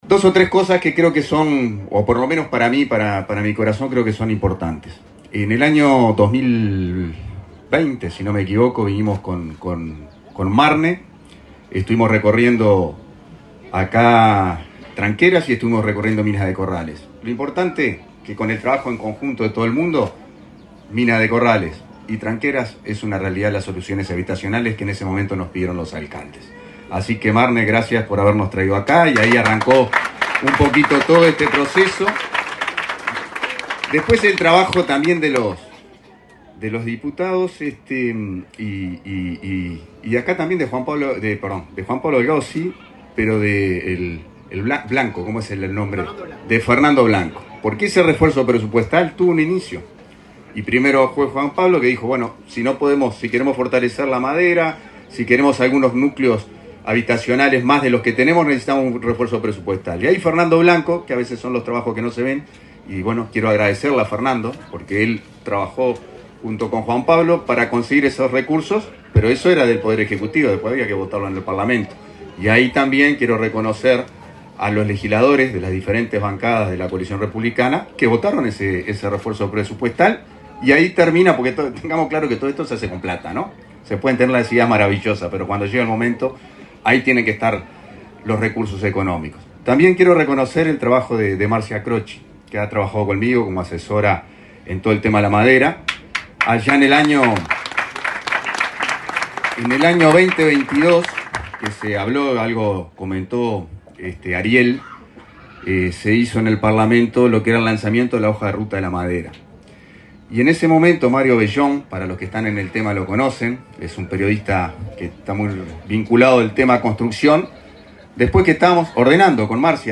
Palabras del ministro interino de Vivienda, Tabaré Hackenbruch
Palabras del ministro interino de Vivienda, Tabaré Hackenbruch 14/11/2024 Compartir Facebook X Copiar enlace WhatsApp LinkedIn El ministro interino de Vivienda, Tabaré Hackenbruch, participó, este jueves 14, en la inauguración de 24 viviendas, de un total de 64, en la localidad de Tranqueras, departamento de Rivera.